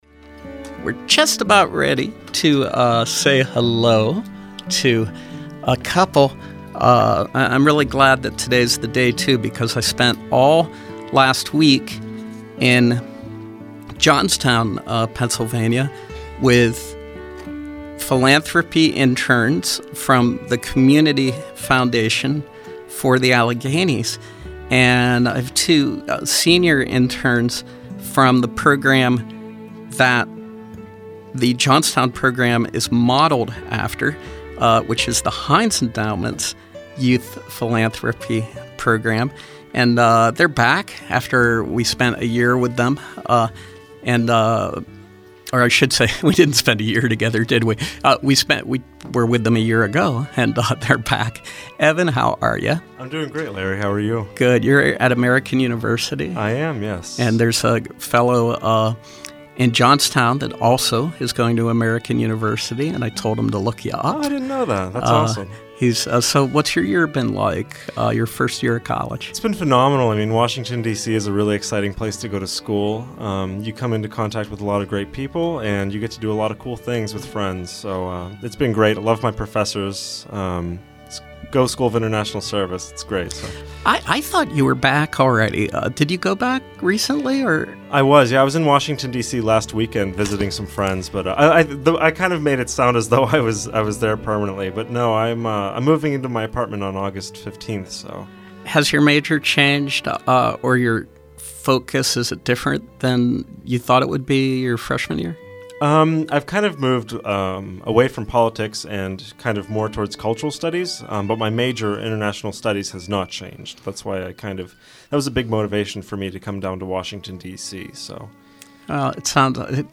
Interview: Youth philanthropy Interns